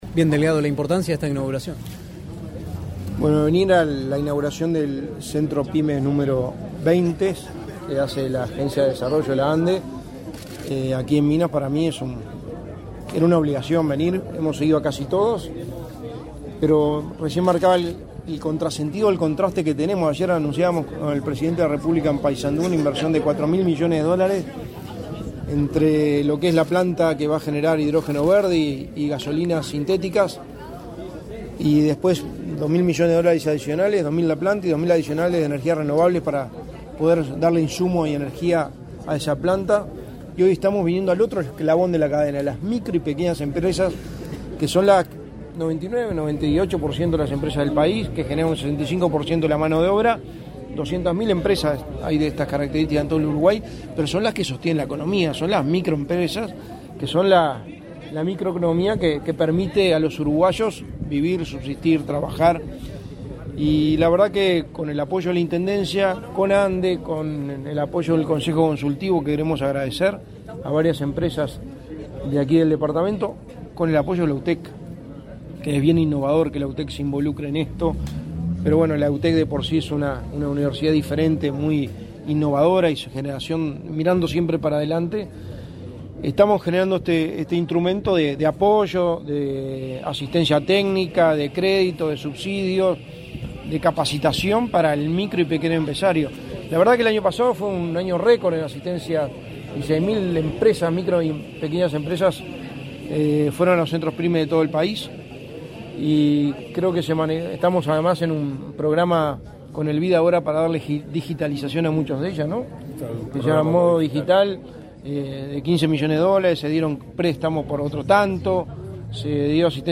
Declaraciones del secretario de la Presidencia, Álvaro Delgado
Declaraciones del secretario de la Presidencia, Álvaro Delgado 09/06/2023 Compartir Facebook X Copiar enlace WhatsApp LinkedIn Tras participar en la inauguración del Centro Pyme de Lavalleja, este 9 de junio, el secretario de la Presidencia, Álvaro Delgado, realizó declaraciones a Comunicación Presidencial y a medios locales.